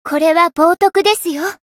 灵魂潮汐-薇姬娜-互动-不耐烦的反馈2.ogg